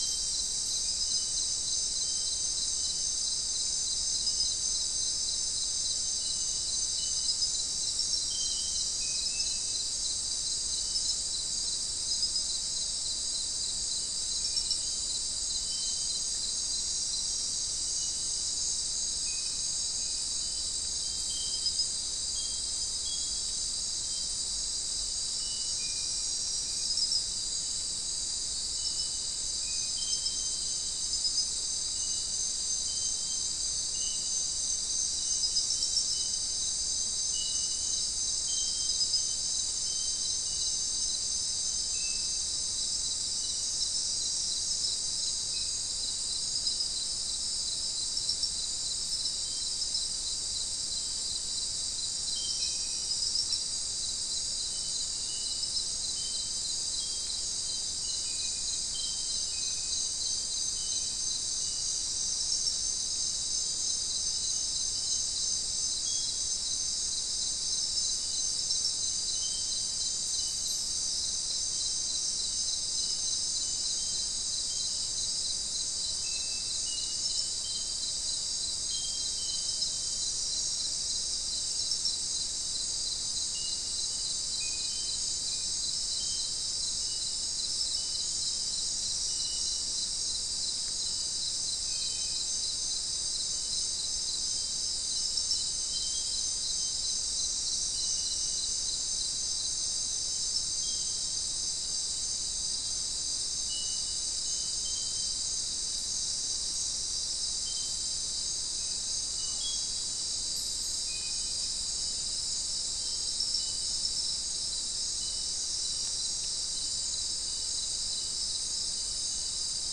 Non-specimen recording: Soundscape
Location: South America: Guyana: Turtle Mountain: 1
Recorder: SM3